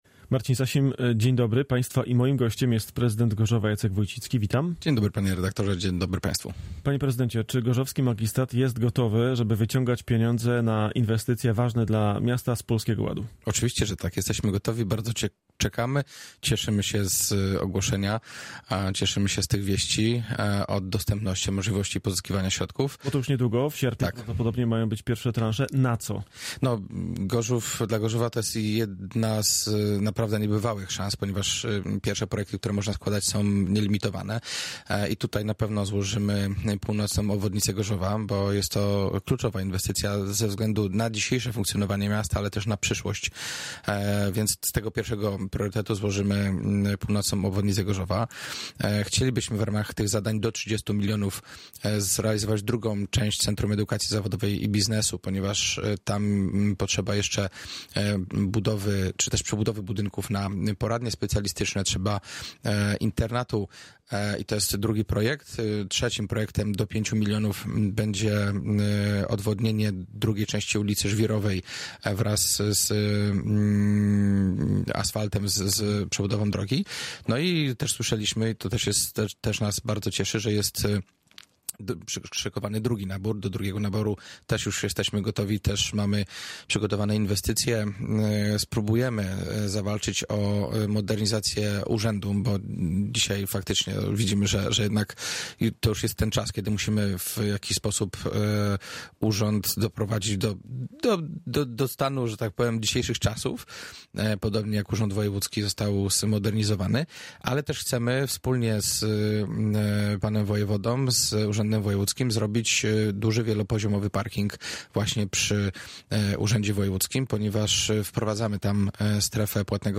Jacek Wójcicki, prezydent Gorzowa